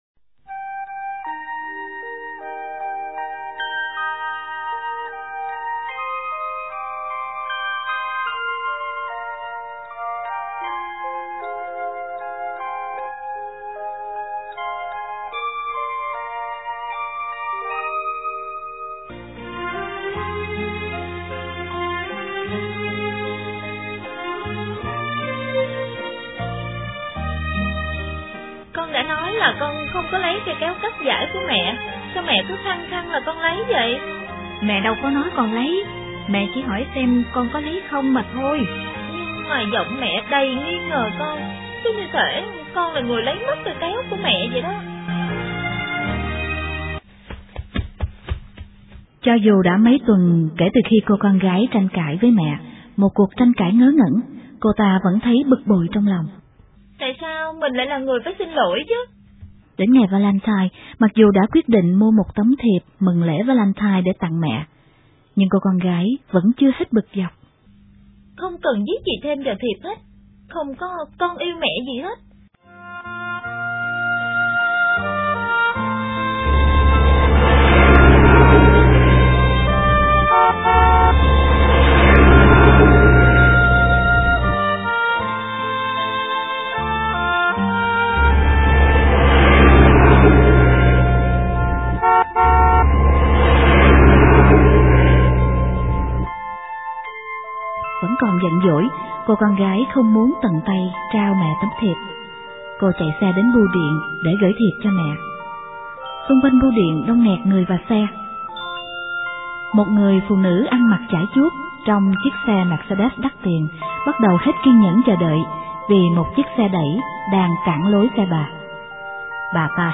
* Thể loại: Sách nói